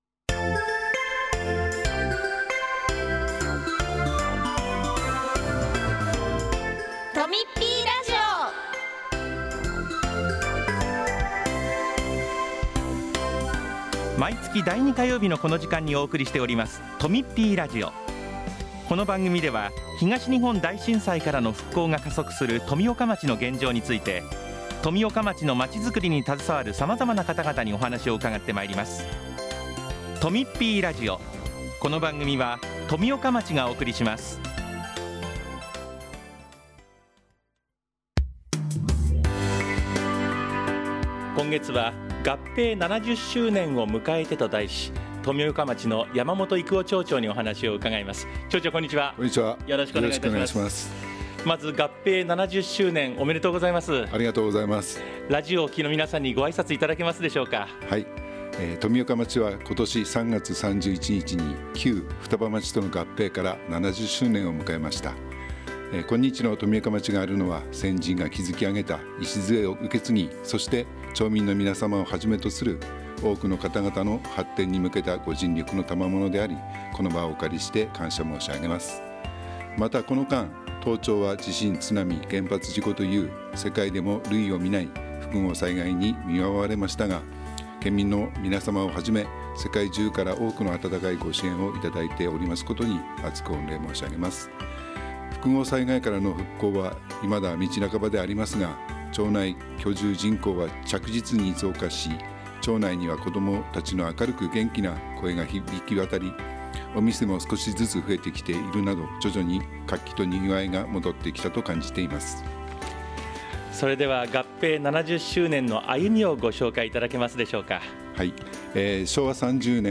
今回は、合併70周年を迎えた富岡町について、 山本育男町長がお話ししています。